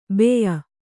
♪ beya